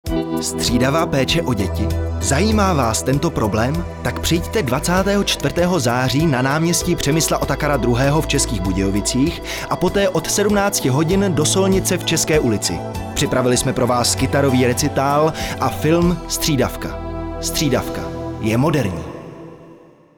zvuková upoutávka (.mp3; 793 kB)